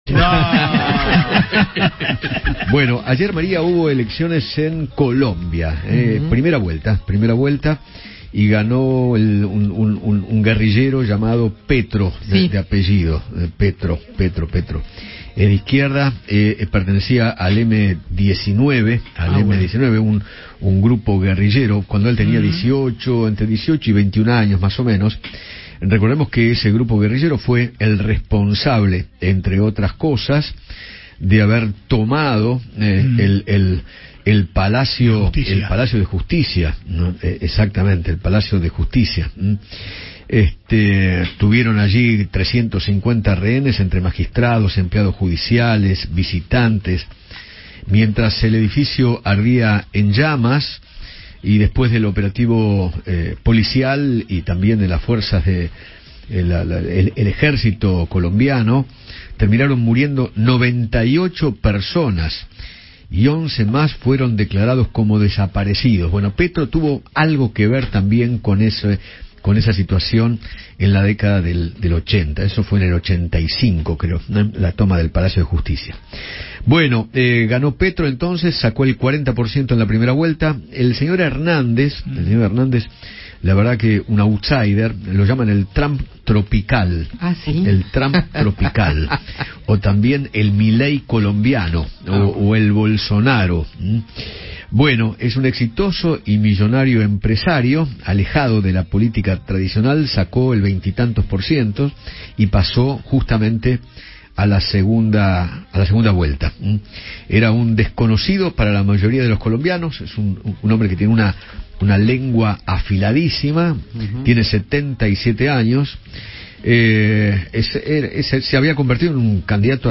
Victoria Villarruel, diputada de Libertad Avanza, dialogó con Eduardo Feinmann y analizó las elecciones presidenciales en Colombia, tras su participación como veedora internacional.